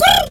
pigeon_2_stress_02.wav